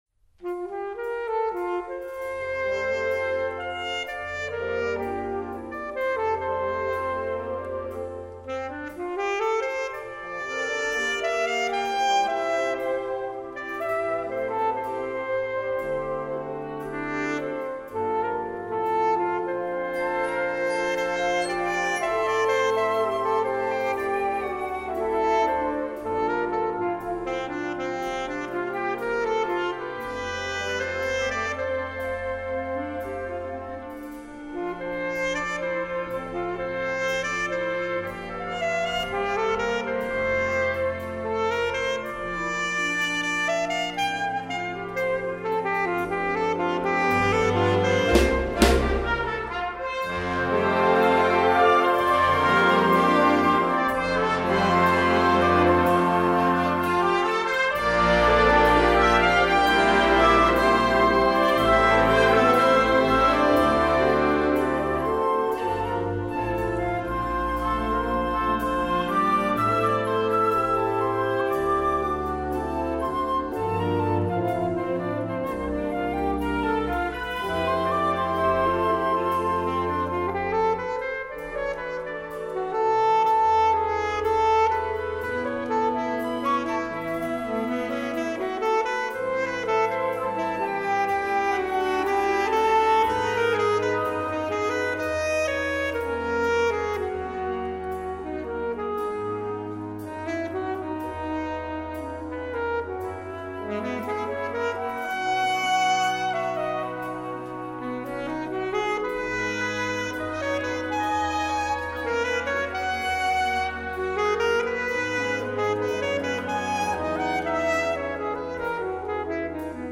Solo für Altsaxophon Schwierigkeit
Blasorchester Zu hören auf